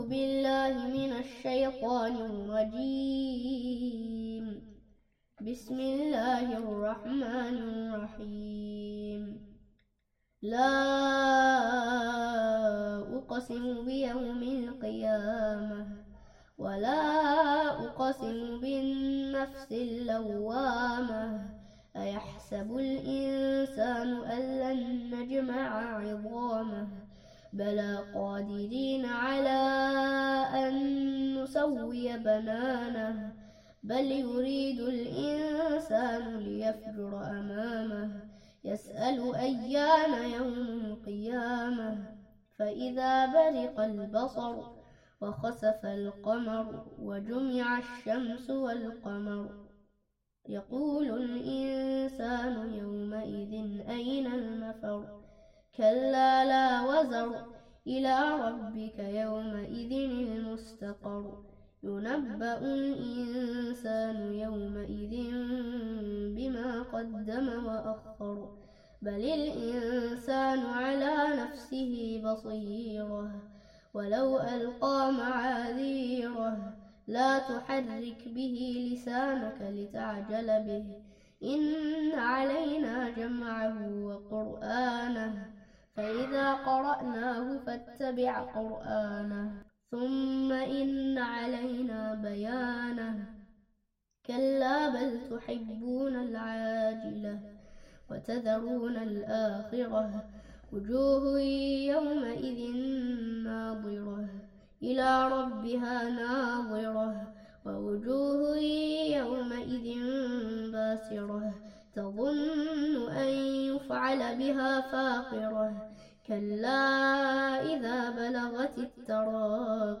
Quran recitation